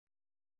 ♪ īṛal